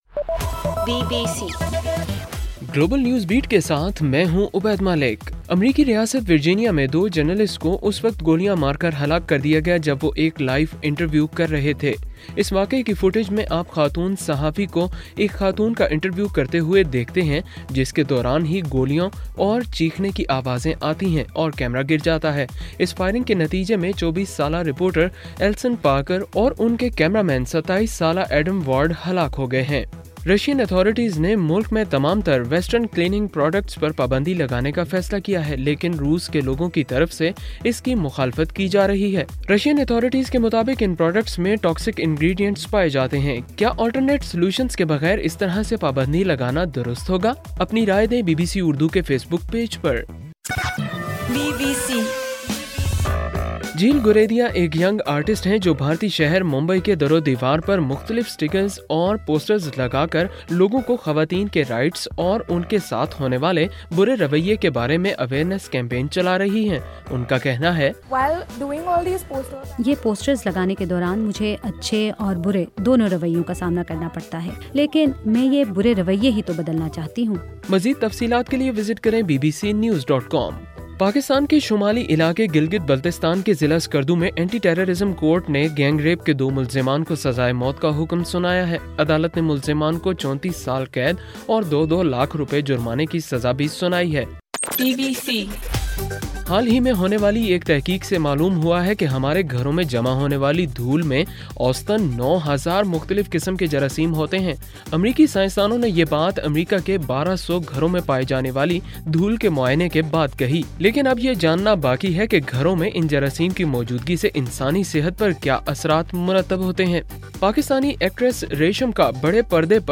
اگست 26: رات 9 بجے کا گلوبل نیوز بیٹ بُلیٹن